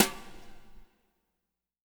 BW BRUSH03-R.wav